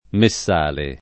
[ me SS# le ]